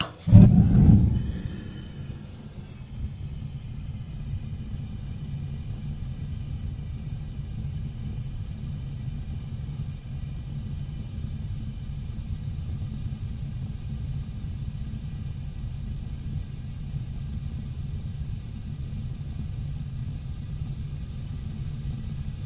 На этой странице собраны звуки работающей газовой колонки – от розжига до равномерного гудения.
Звук воспламенения огня в бытовой газовой колонке